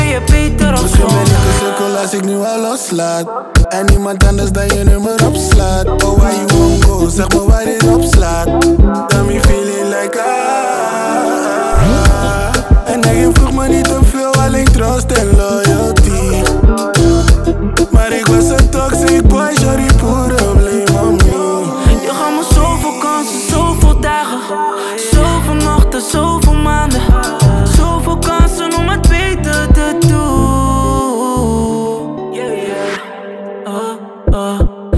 Жанр: Африканская музыка
# Afro-Beat